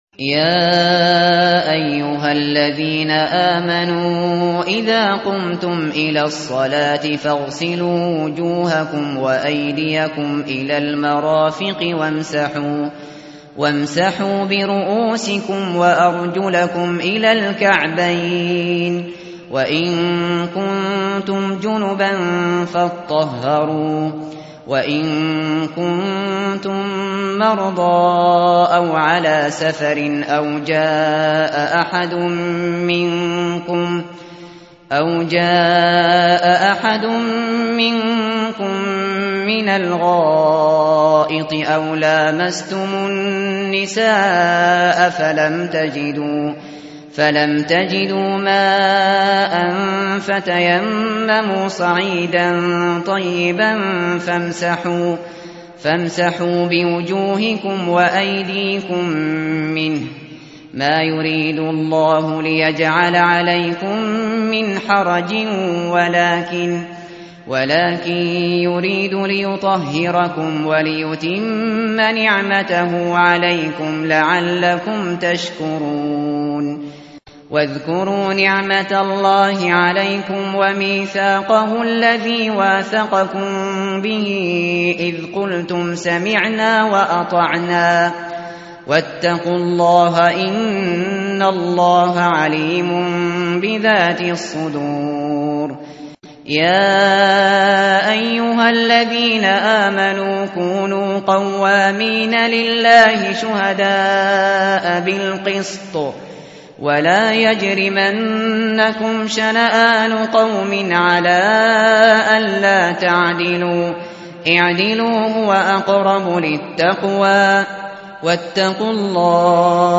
متن قرآن همراه باتلاوت قرآن و ترجمه
tartil_shateri_page_108.mp3